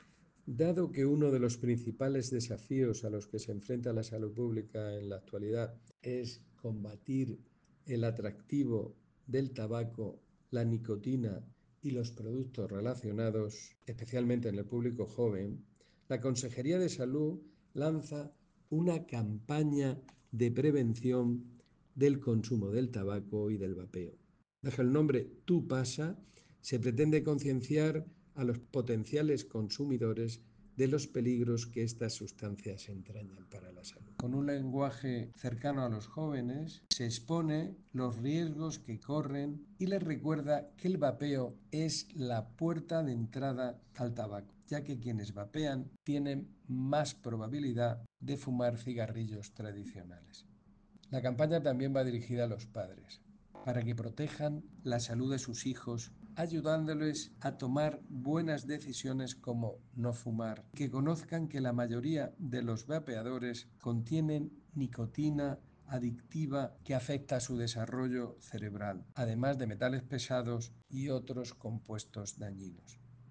Sonido/ Declaraciones del director general de Salud Pública y Adicciones, José Jesús Guillén, sobre la campaña de prevención del consumo de vapeadores y tabaco.